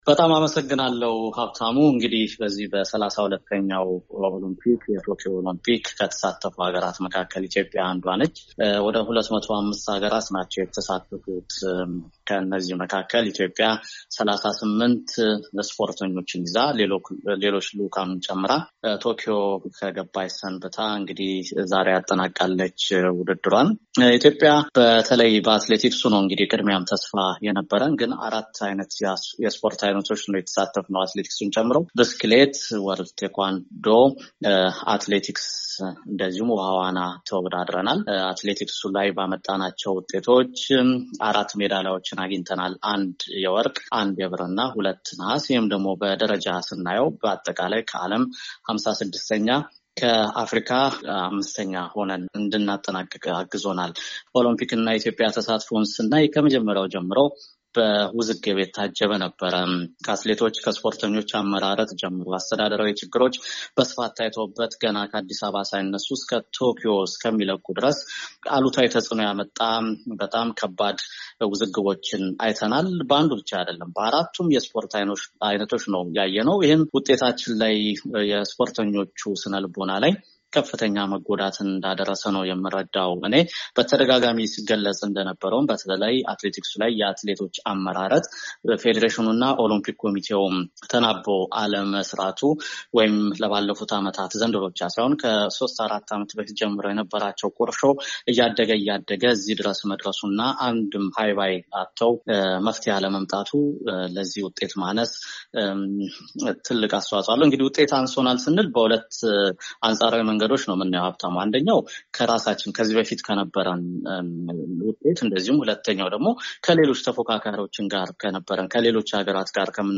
ትናንት አመሻሹን በስልክ አግኝቷል።